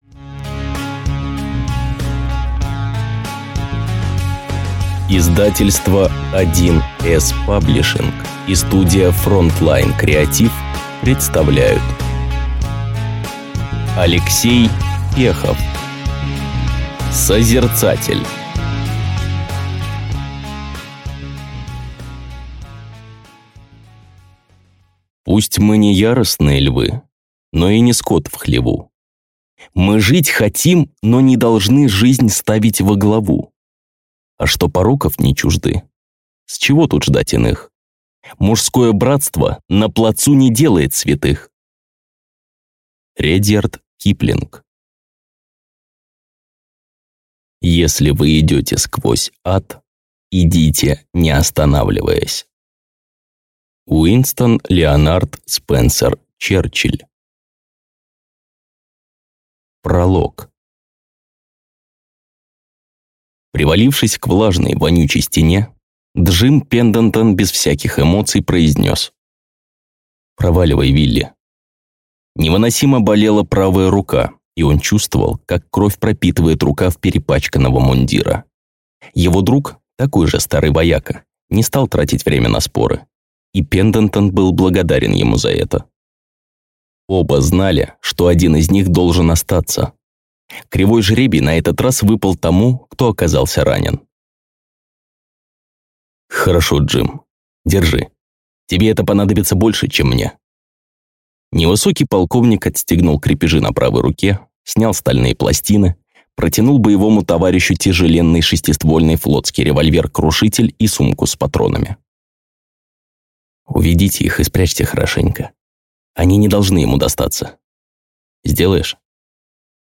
Прослушать фрагмент аудиокниги Созерцатель Алексей Пехов Фантастика Городское фэнтези Детективная фантастика Произведений: 31 Скачать бесплатно книгу Скачать в MP3 Вы скачиваете фрагмент книги, предоставленный издательством